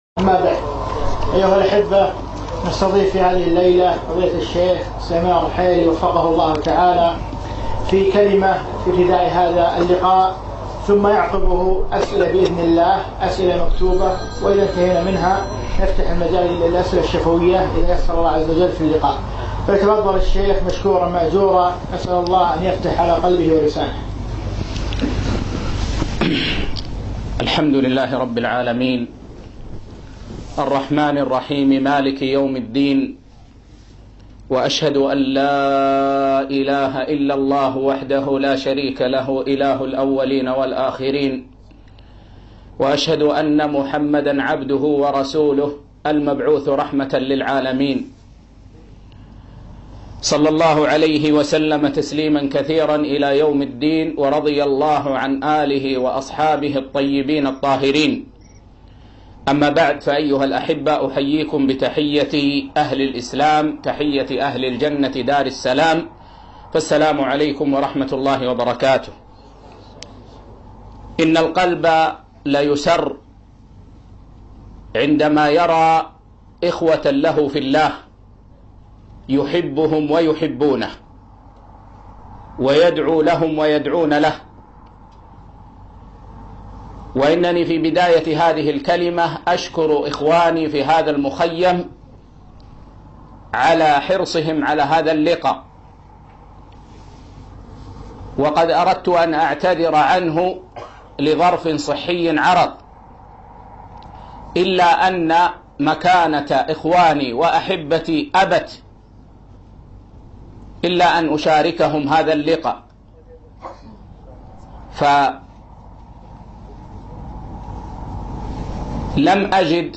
كلمة - عند مشروع زاد المسلم